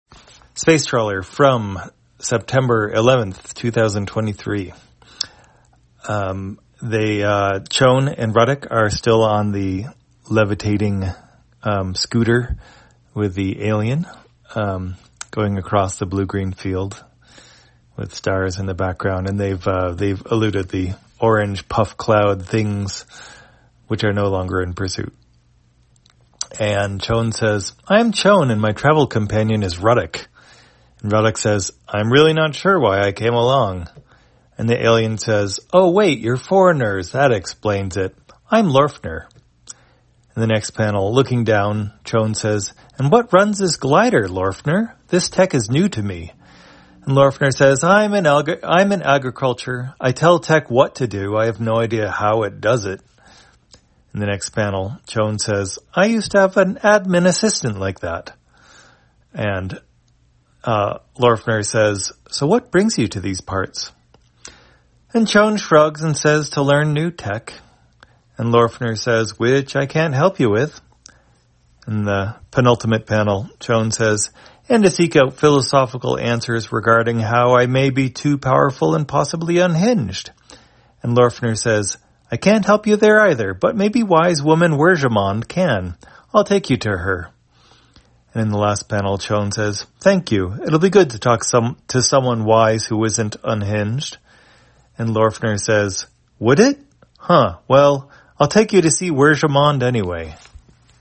Spacetrawler, audio version For the blind or visually impaired, September 11, 2023.